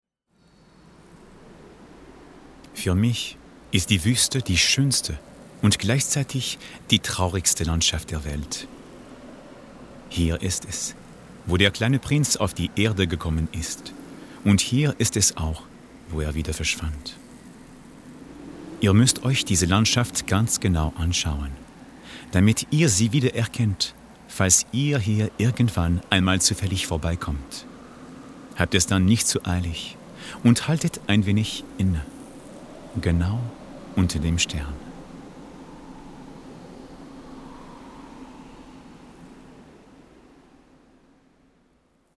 DEmitleichtemFRAkzent-Hörbuch-Erzählung-DieBoa
Audiobook (Hörbuch), Narrative, Scene, Tale (Erzählung)